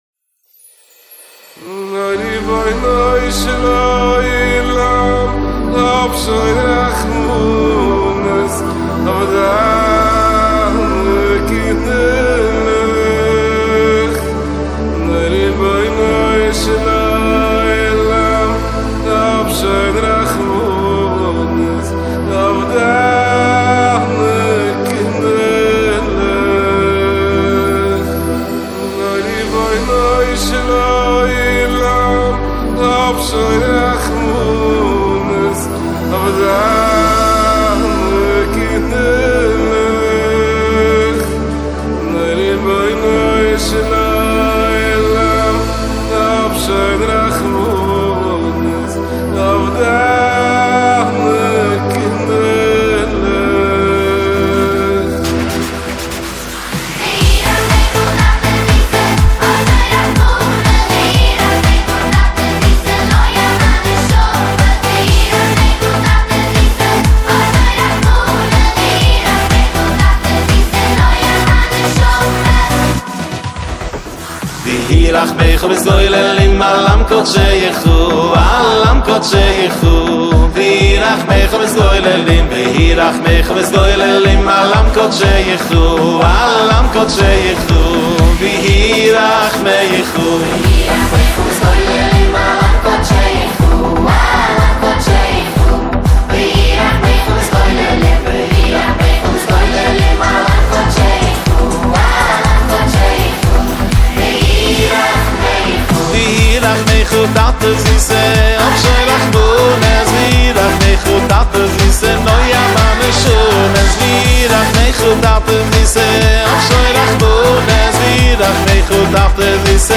אך בהמשך מתפתח לקצב ריקודים בעיבוד אלקטרוני עדכני.